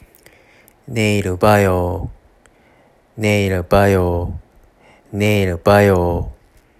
「また、明日」の発音